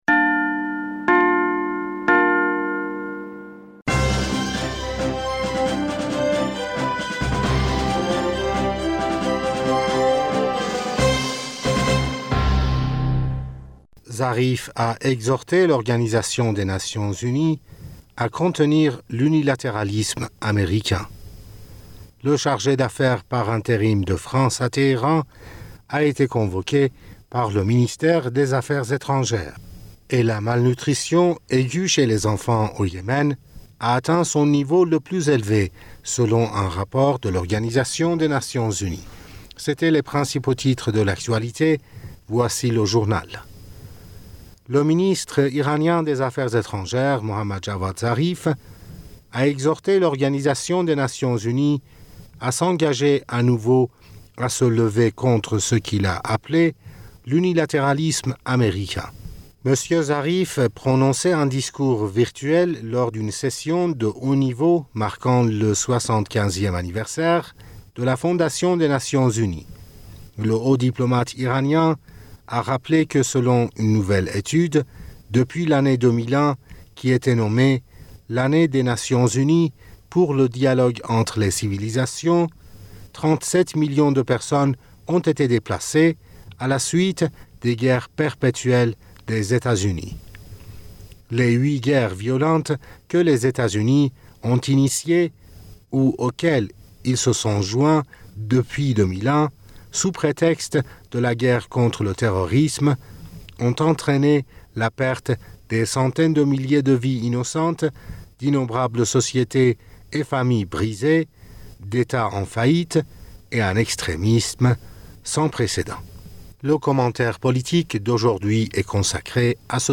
Bulletin d'informationd u 27 Octobre 2020